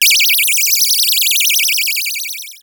Shoot14.wav